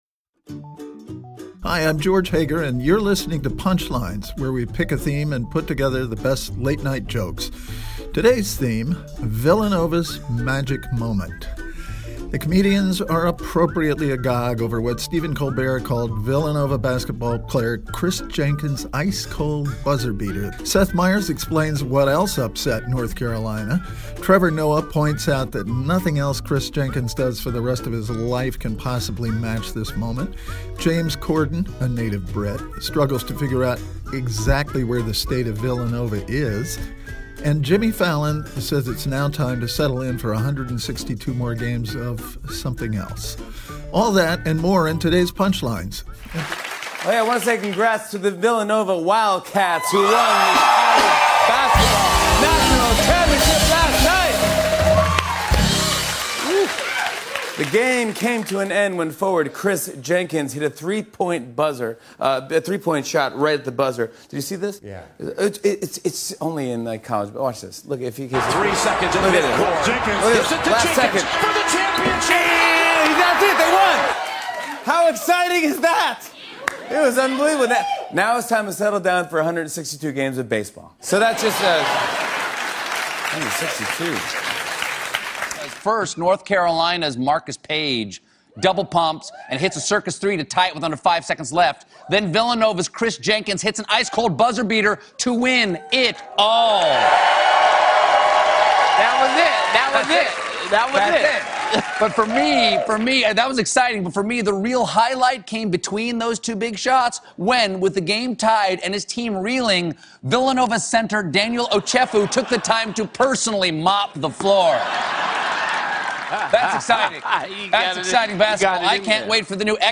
The late-night comics reflect on the buzzer-winning shot that gave Villanova the men's NCAA championship.